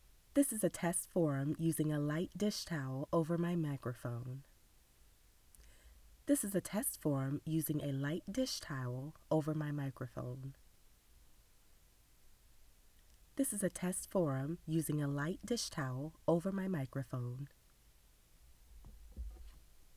I don’t think the overly crisp gritty sound is you.
I have posted my the audio with a light dish towel over the microphone below.
The Dish Towel version has better tonal balance, but the towel reduces the overall volume and you don’t have enough volume to begin with. This causes the track to violate the noise specification, which causes tonal distortions, which causes… etc, etc, etc, etc.